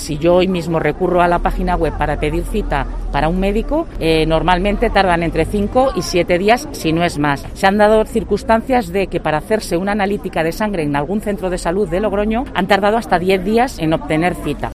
Así protestan los profesionales de la Enfermería y la Fisioterapia de La Rioja